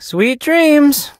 sandy_kill_vo_04.ogg